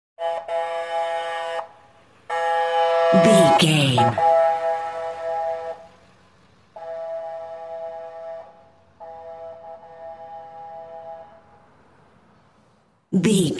Ambulance Ext Passby Horn
Sound Effects
urban
chaotic
emergency